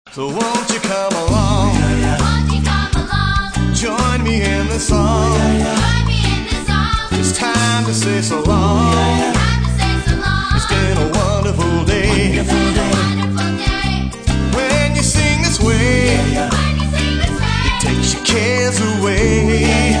Transition Song for Young Children